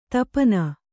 ♪ tapana